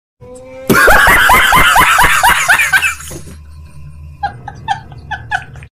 HAha Funny Laugh Meme Sound Effects Free Download
HAha funny laugh Meme sound effects free download